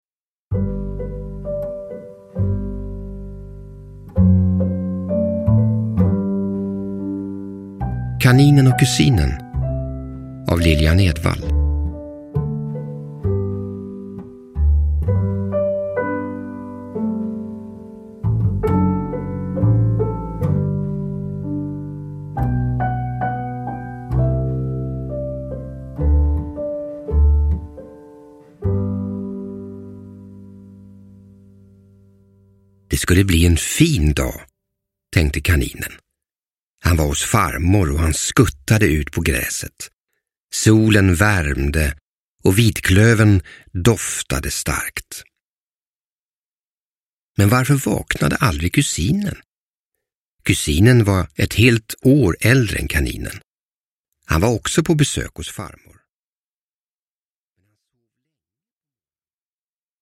Kaninen och Kusinen – Ljudbok – Laddas ner